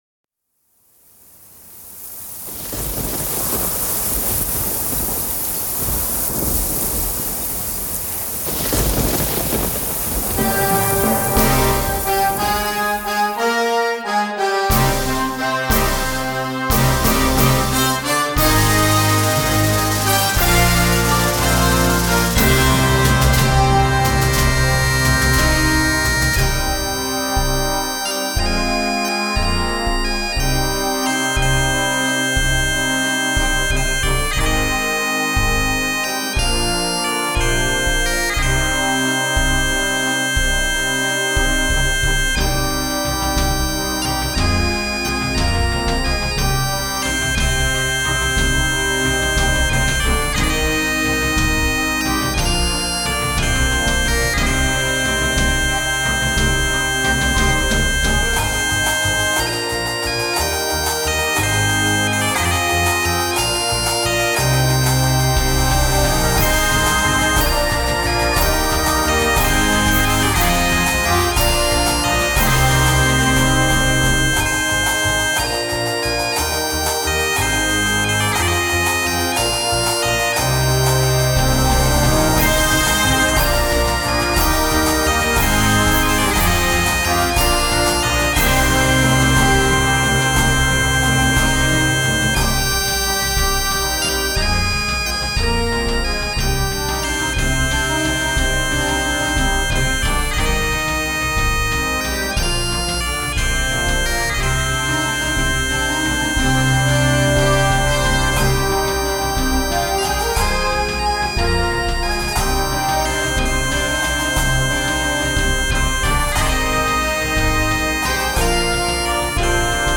CategoryConcert Band & Bagpipes
Timpani
Side Drum
Tubular Bells